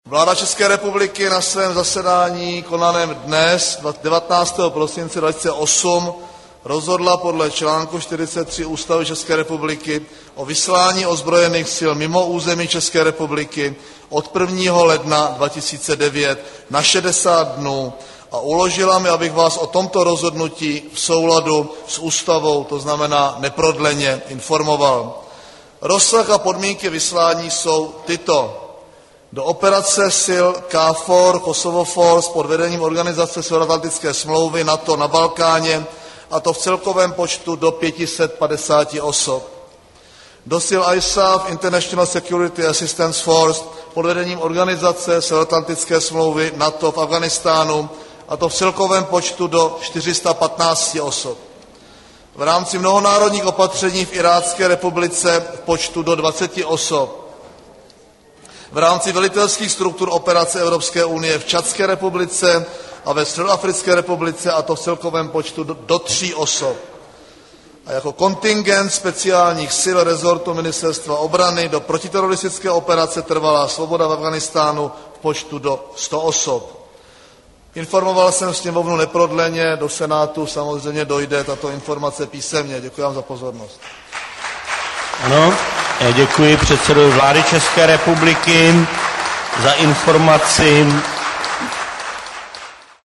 Zvukový záznam vystoupení premiéra Mirka Topolánka v Poslanecké sněmovně Parlamentu ČR